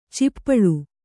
♪ cippaḷu